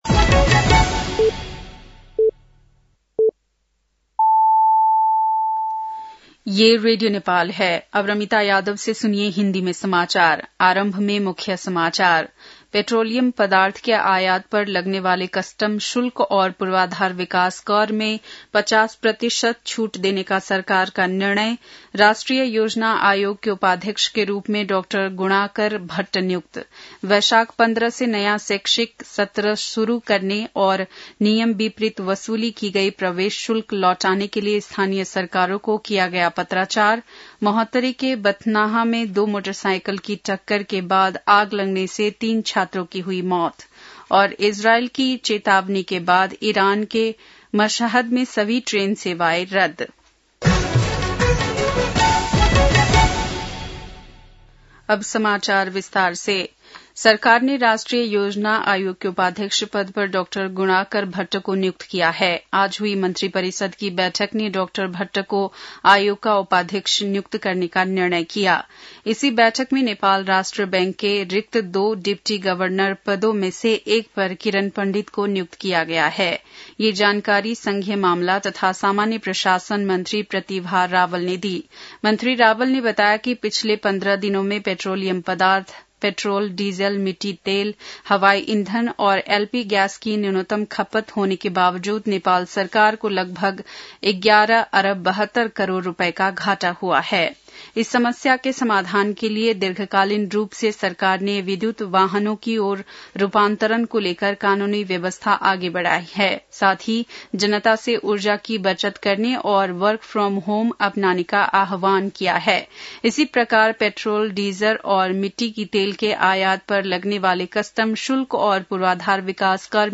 बेलुकी १० बजेको हिन्दी समाचार : २४ चैत , २०८२